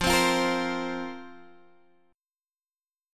Fsus4 chord